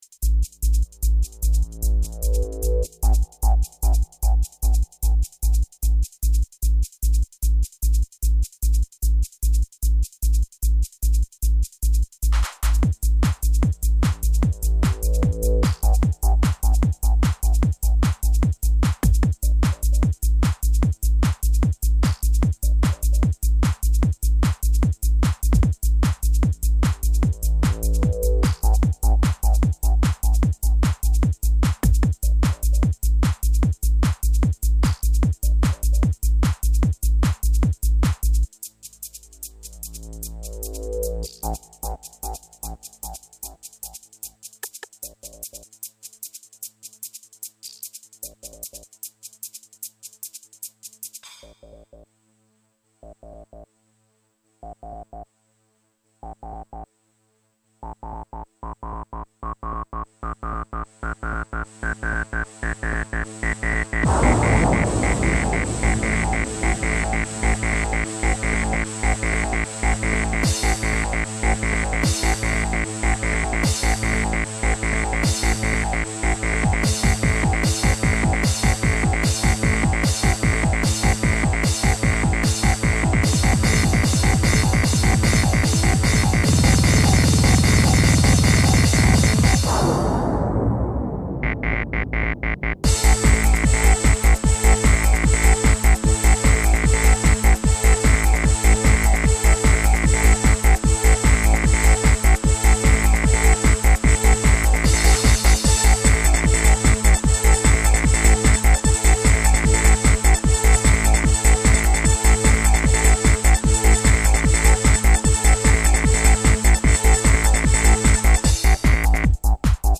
/nu-NRG   MIDI(57 kB)
単一音階でどこまでノれるか、というテーマで作ってみました。